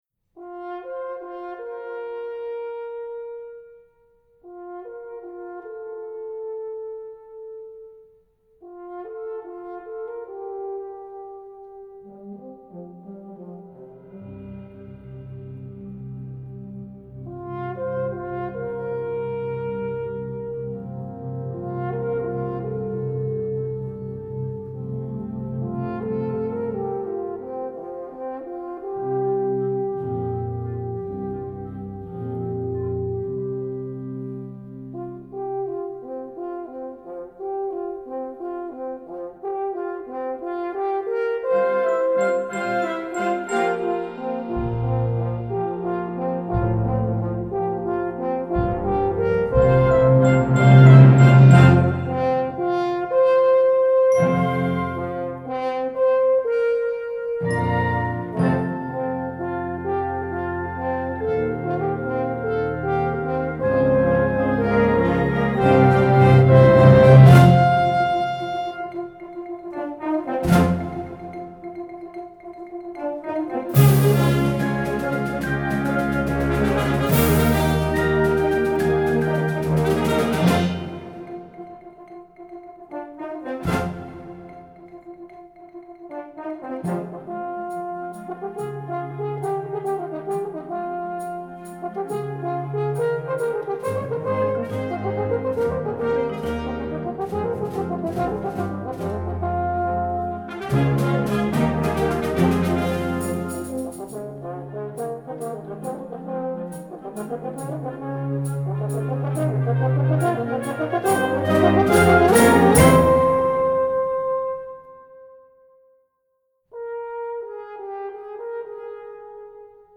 Gattung: Solo für Horn in F/Es und Blasorchester
Besetzung: Blasorchester